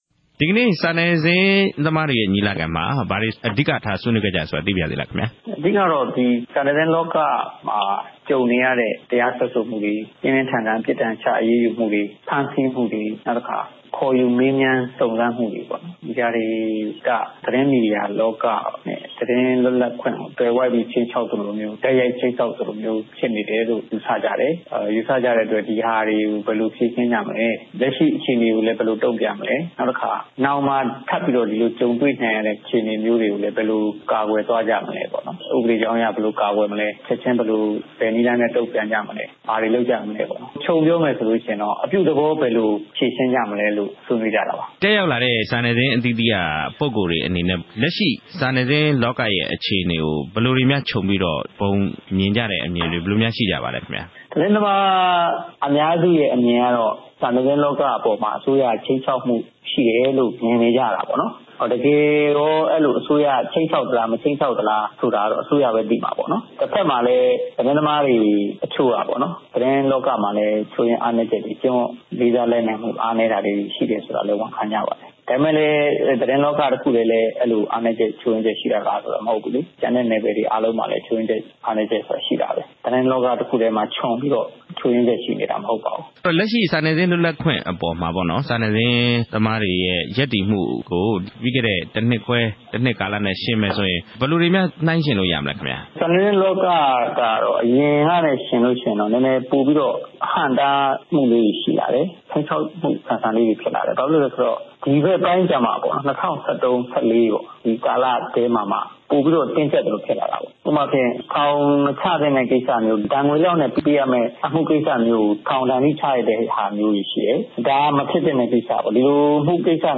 မြန်မာသတင်းမီဒီယာ ခြိမ်းခြောက်ဖိနှိပ်မှု ဆက်သွယ်မေးမြန်းချက်